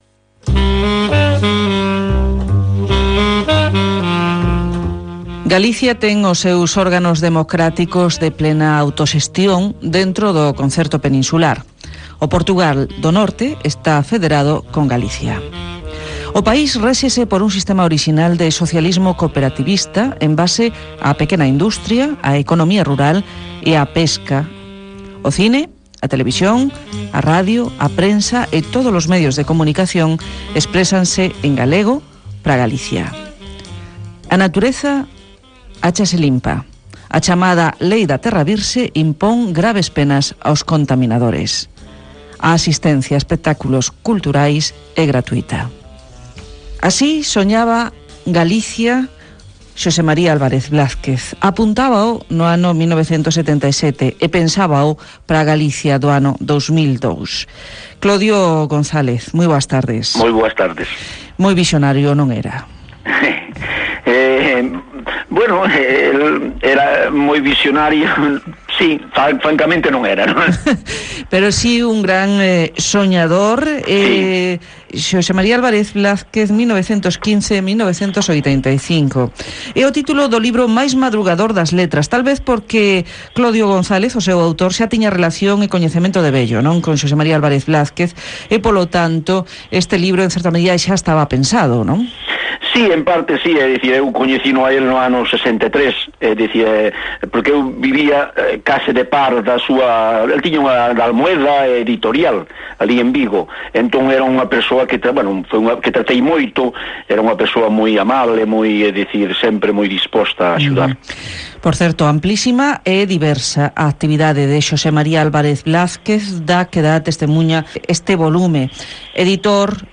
Entrevista
no Diário Cultural da Rádio Galega.